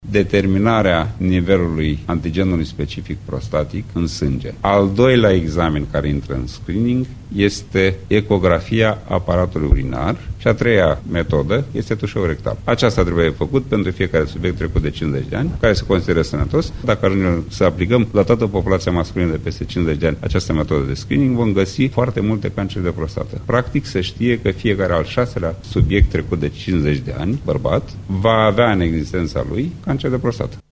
Medicul Ioanel Sinescu a precizat ce analize ar trebui să facă barbaţii cu vârste de peste 50 de ani, respectiv 40 de ani – dacă există un istoric familial în privinţa cancerului de prostată: